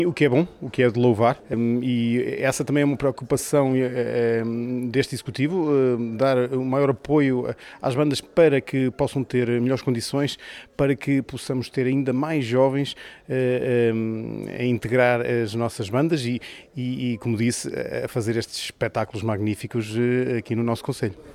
Um sinal positivo para o futuro, destaca o presidente da Câmara Municipal de Macedo de Cavaleiros, Sérgio Borges:
ntrevistas